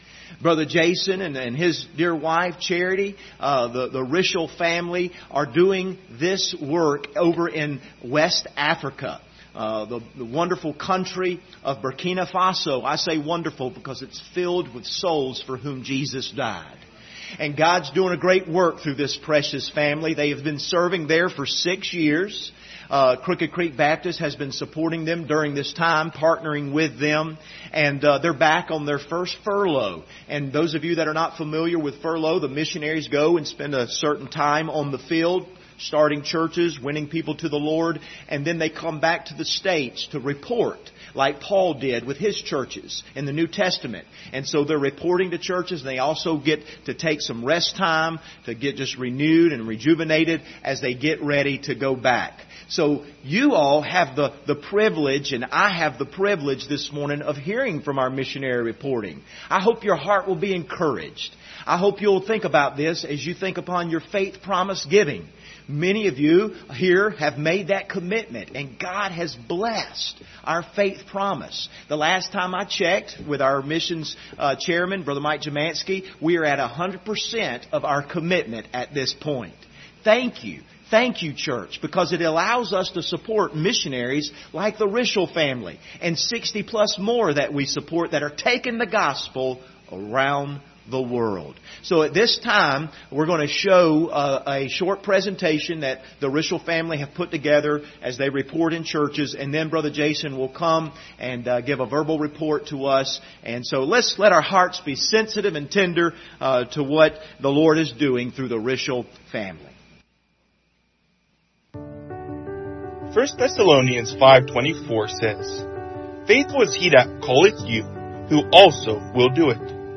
Missionary Report
Service Type: Sunday Morning